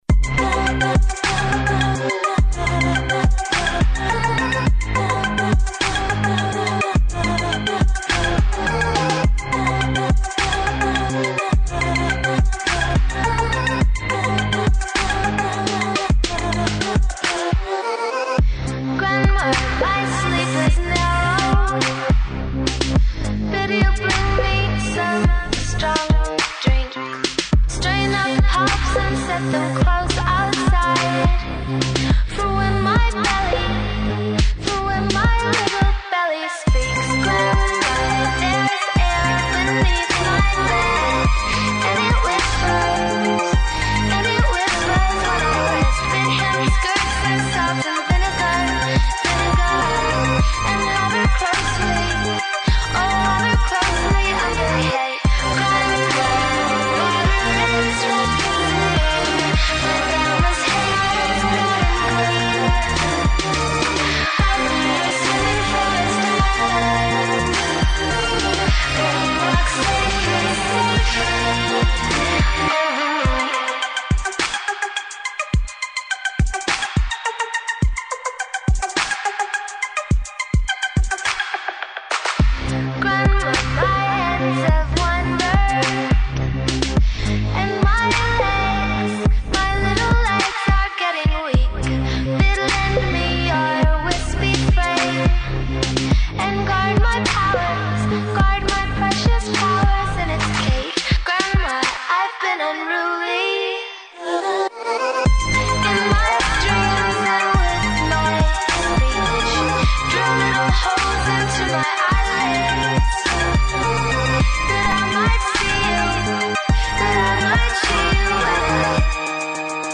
Canadian dark electro pop band